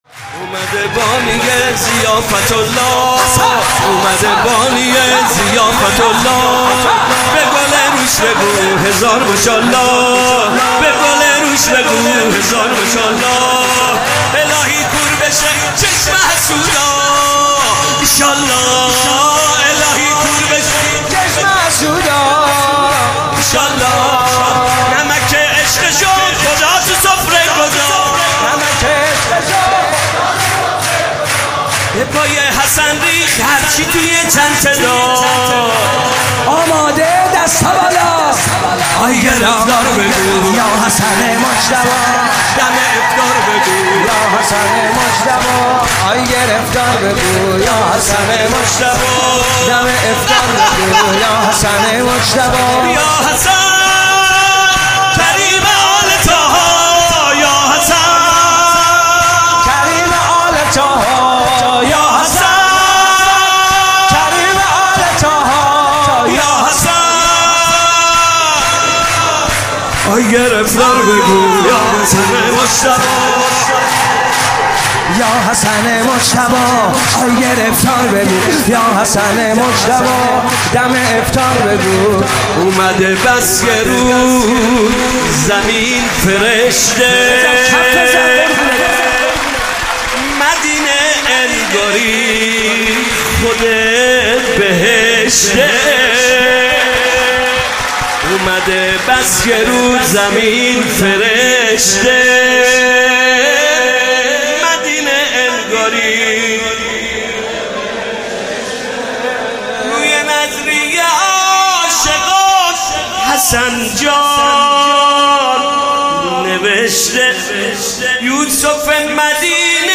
مولودی خوانی
ویژه ولادت امام حسن مجتبی (علیه السلام)